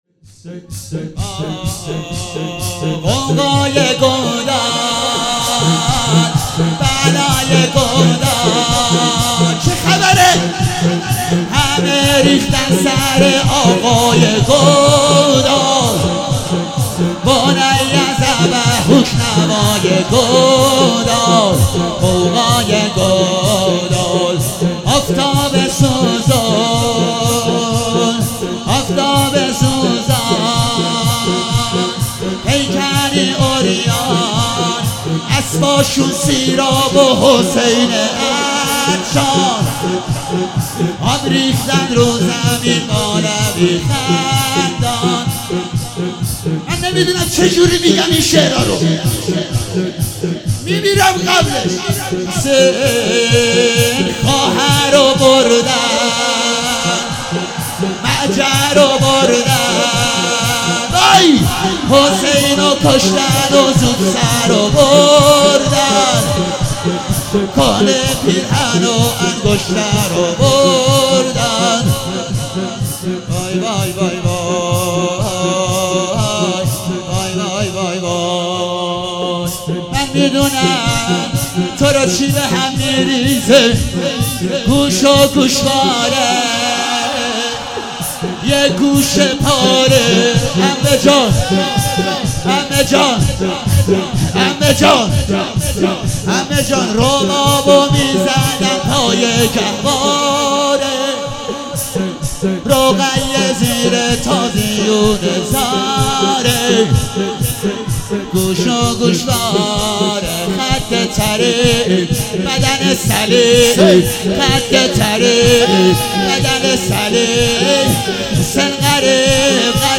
شور - غوغای گودال بلای گودال